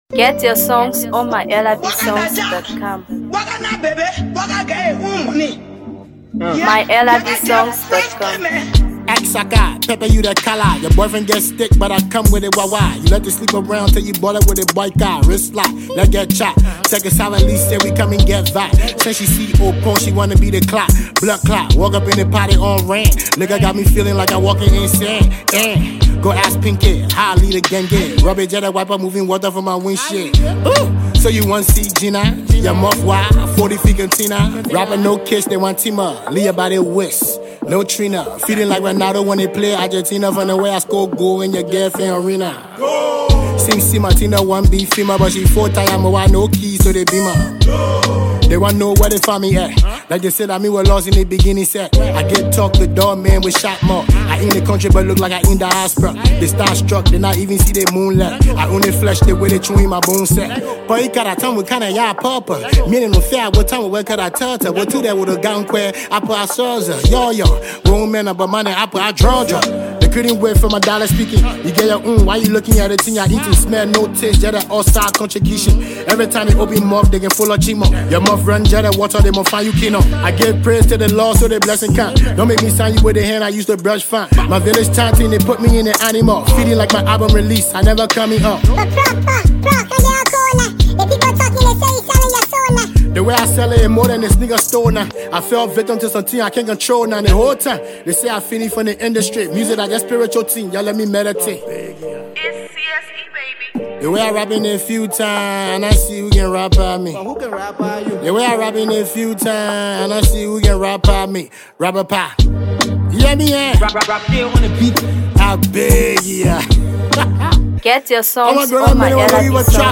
MusicTrapco
Beyond its catchy lyrics and pulsating rhythms